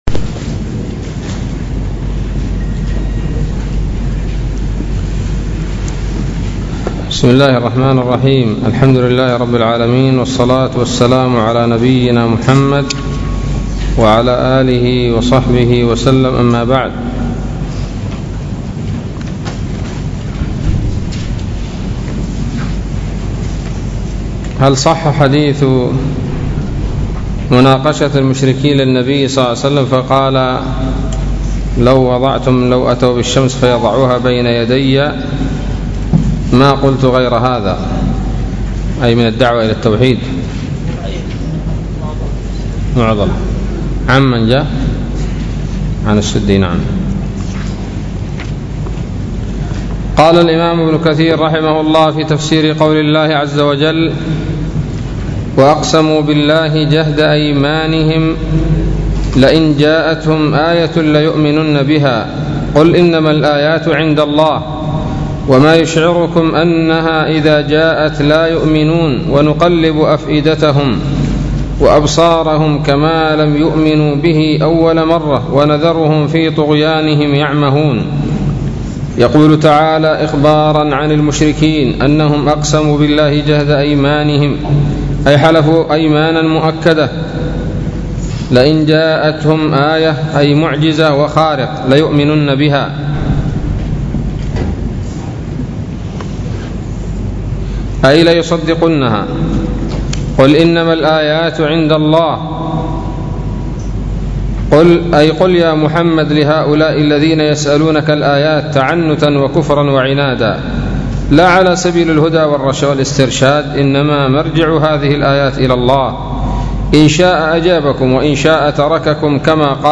الدرس الأربعون من سورة الأنعام من تفسير ابن كثير رحمه الله تعالى